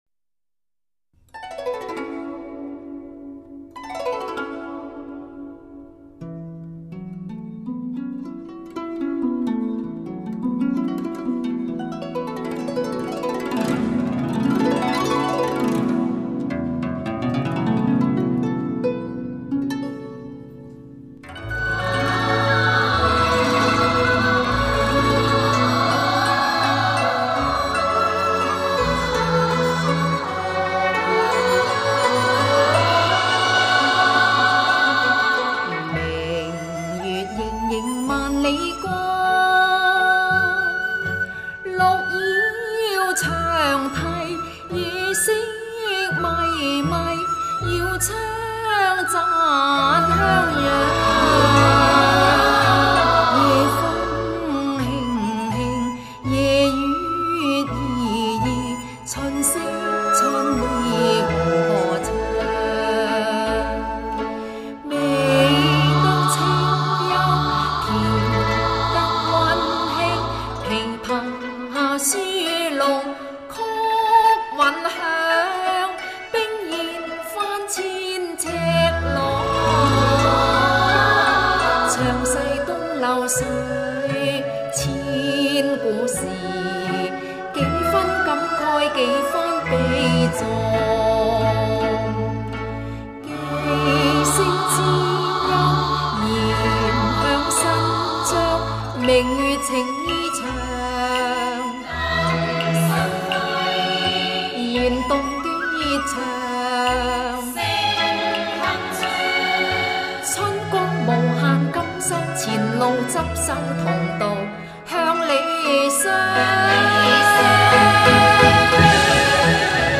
錄音地點：廣州市老易文化傳播有限公司